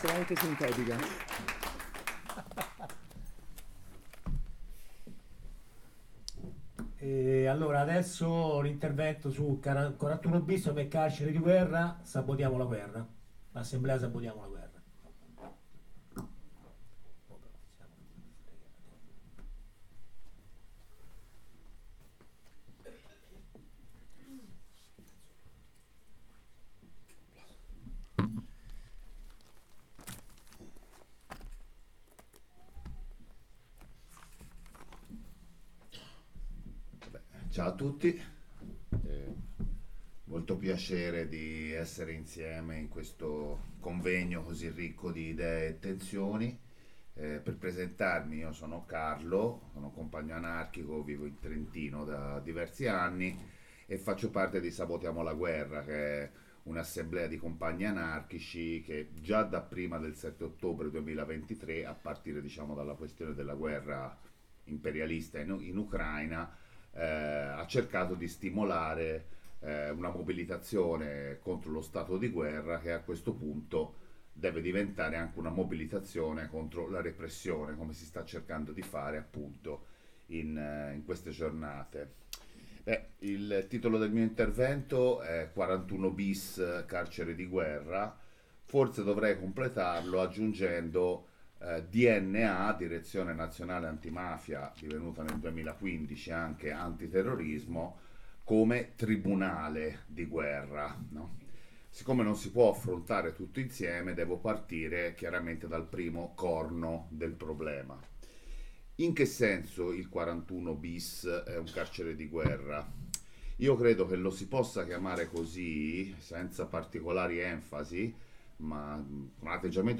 Questo intervento, fatto “a braccio” durante il convegno, registrato e poi trascritto, compare qui in una versione leggermente rielaborata e arricchita. Il compagno che l’ha pronunciato ci tiene a confessare il proprio debito verso i lavori (alcuni dei quali reperibili sul web) del sociologo Charlie Barnao, principale fonte utilizzata per ricostruire le origini della tortura contemporanea su cui si basano i regimi di isolamento torturativo come il 41-bis.